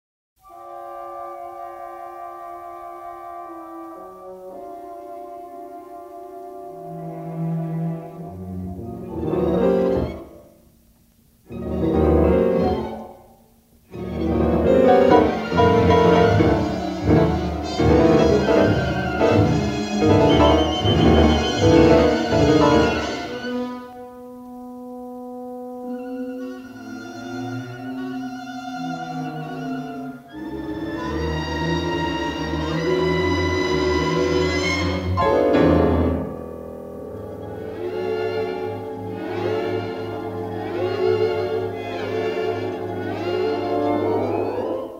the balance of the CD is in mono.